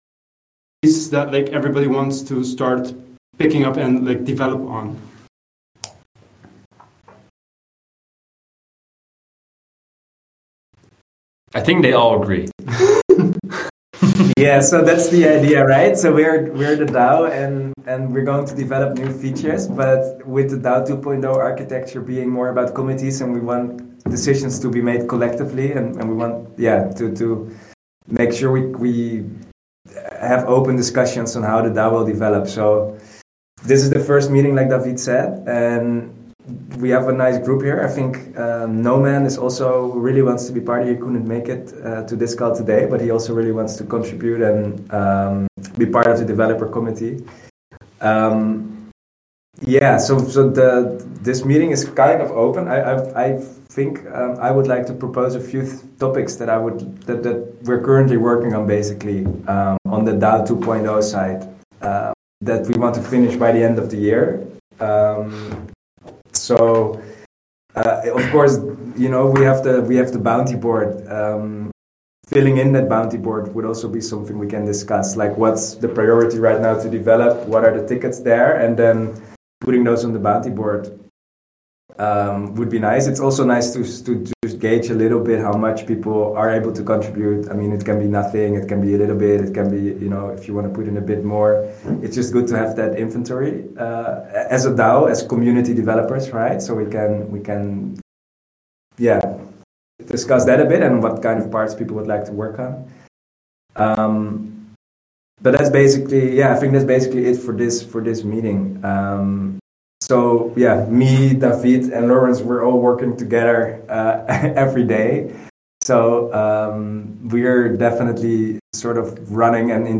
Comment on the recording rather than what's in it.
The recording sort of worked, it cut of I think the first 3 minutes and the last few minutes, but it's not missing important stuff.